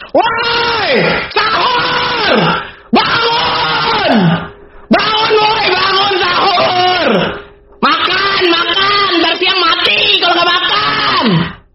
Nada dering alarm Sahur TikTok
Kategori: Nada dering